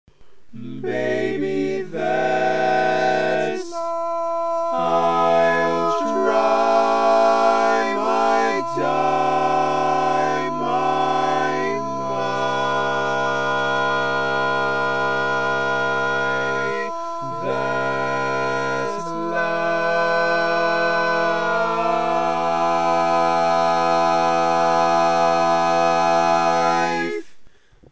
Key written in: F Major
How many parts: 4
Type: Barbershop